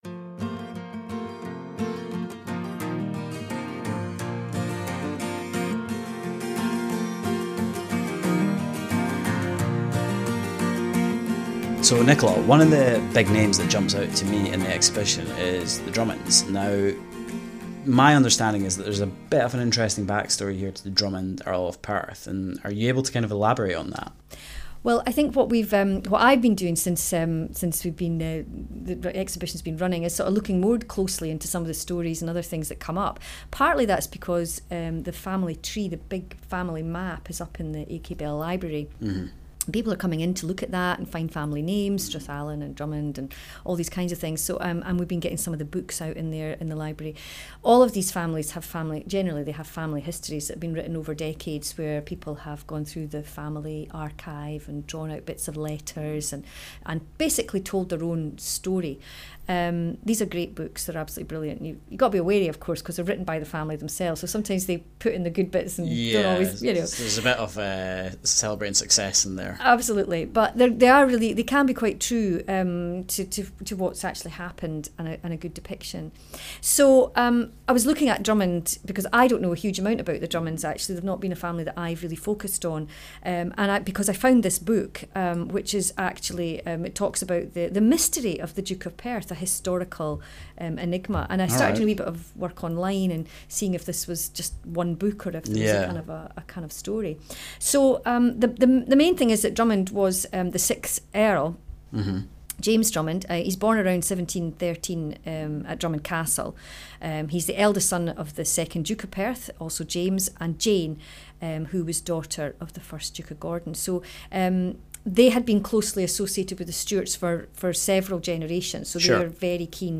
In episode 5 of the Jacobite Clans Podcasts the speakers sit down to discuss some Clan Stories, including that of the Drummond, Earl of Perth.